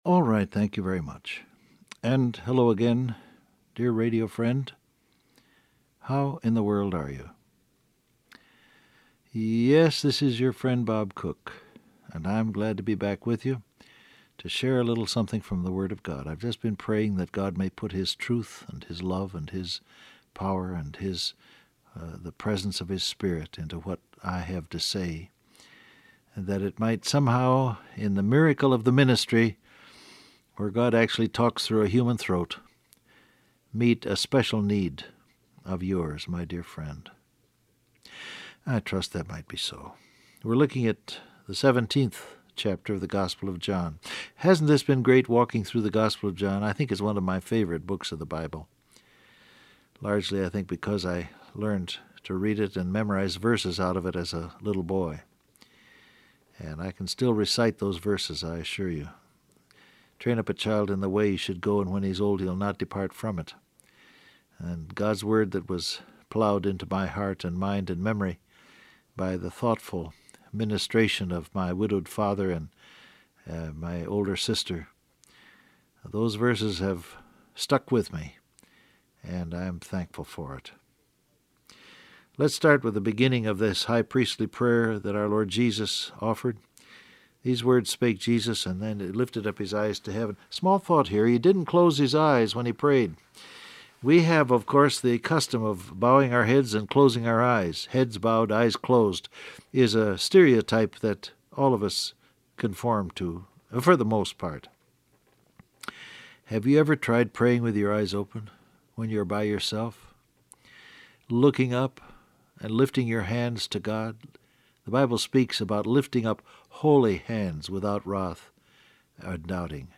Download Audio Print Broadcast #6947 Scripture: John 17:4-8 Topics: Wait On God , Eternal Life , Glorify , Names Of God Transcript Facebook Twitter WhatsApp Alright, thank you very much.